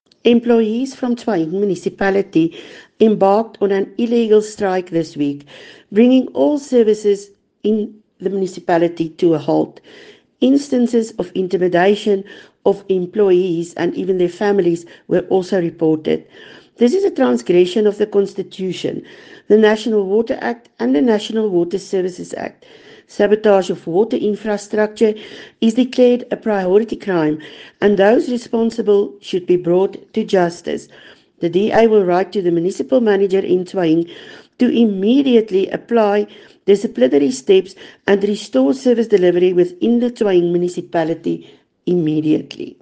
Issued by Soret Viljoen – DA Councillor - Tswaing Local Municipality
Note to Broadcasters: Please find attached soundbite in